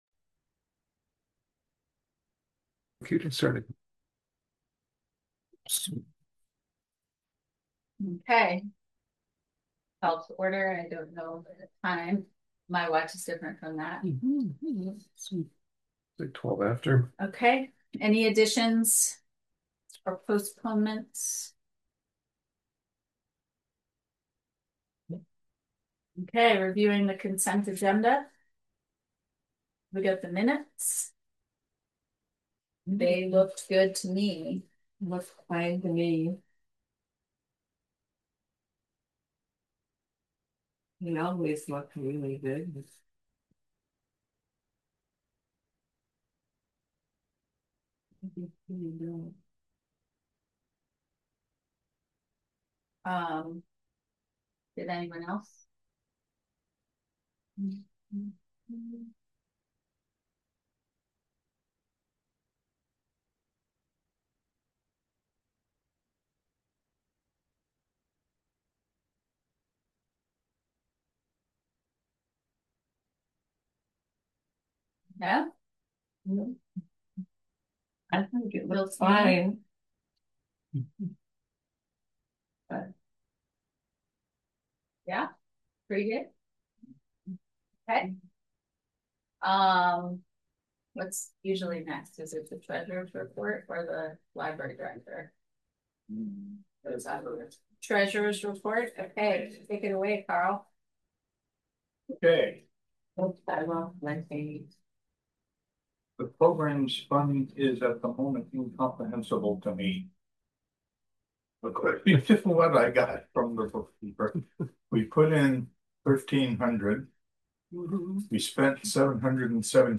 September 11 2025: RFL Board Meeting - Roxbury Free Library
Agenda Packet Meeting Minutes {APPROVED} Meeting Minutes {AUDIO} Roxbury Free Library Board of Trustee’s Meeting September 11 2025 at 9:00 Physical Meeting location: Roxbury Free Library, 1491 Roxbury Rd., Roxbury VT.